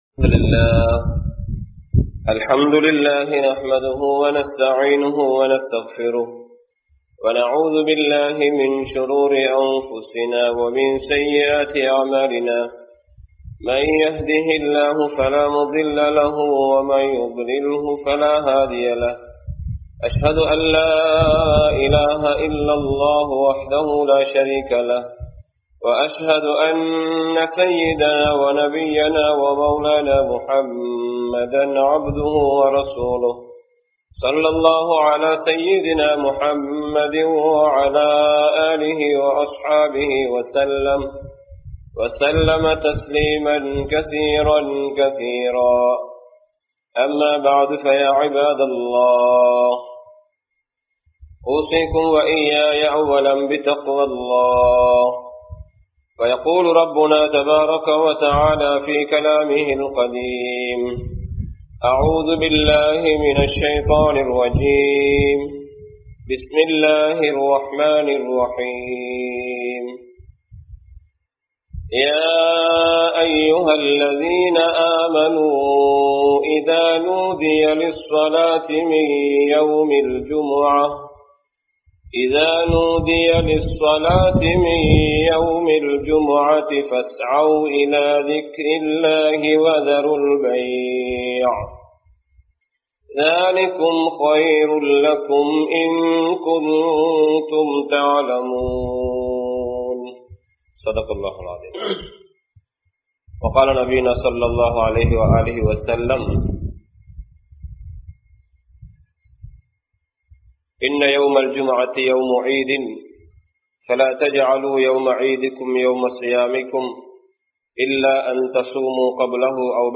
Jumua Thinaththin Sirappuhal (ஜூம்ஆ தினத்தின் சிறப்புகள்) | Audio Bayans | All Ceylon Muslim Youth Community | Addalaichenai